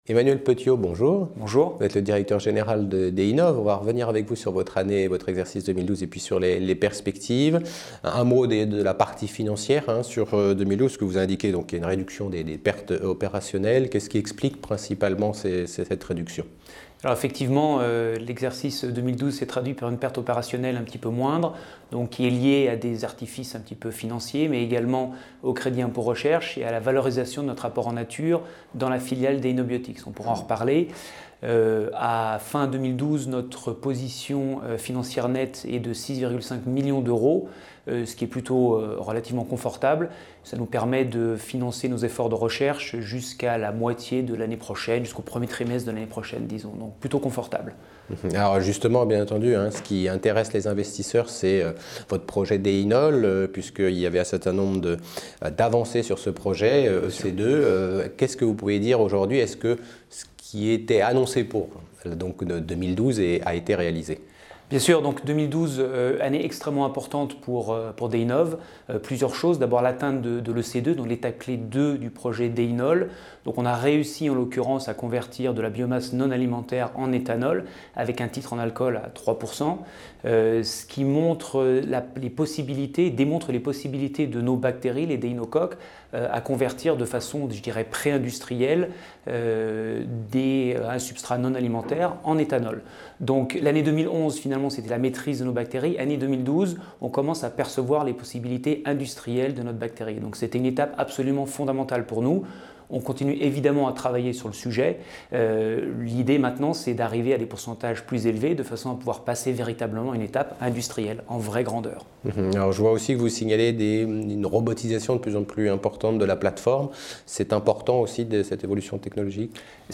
Au sommaire de l’interview TV :